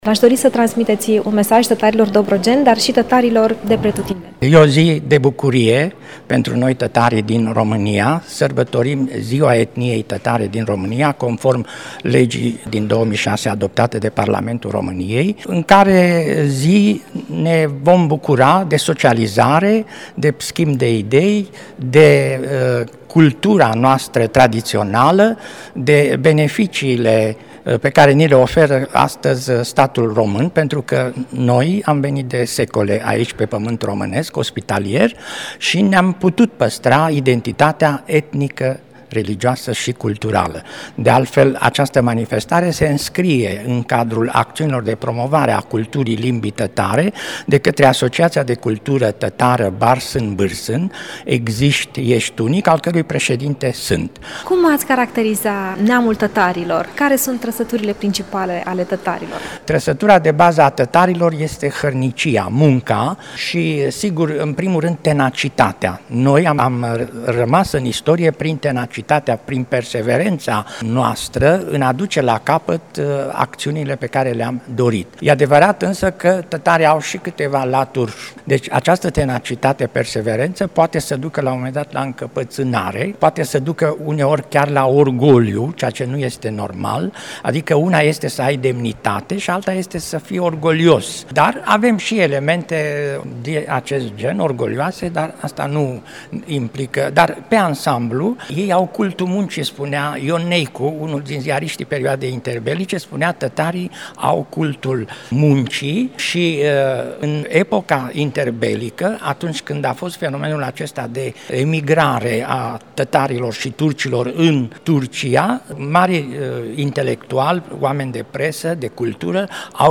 Ziua Etniei Tătare din România, celebrată pe 13 decembrie, a fost sărbătorită și la Constanța printr-un eveniment emoționant la Biblioteca Județeană „Ioan N. Roman”.